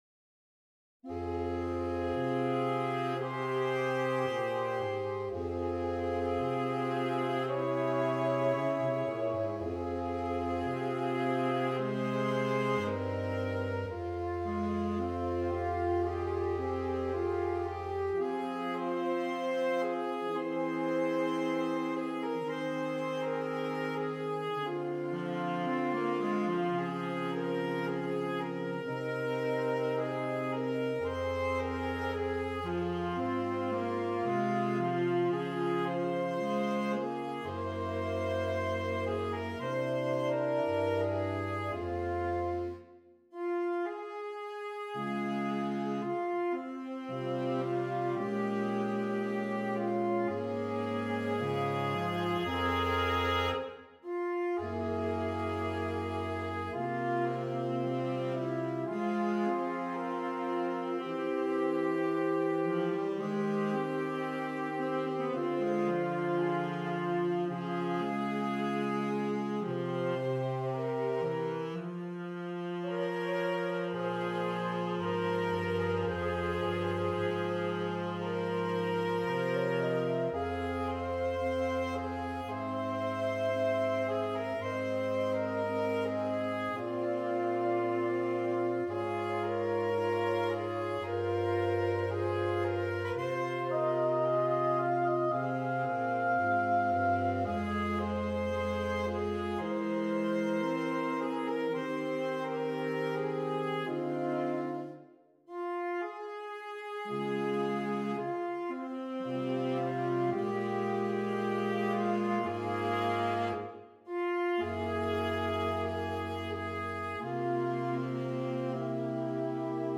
Saxophone Quartet (AATB)
Traditional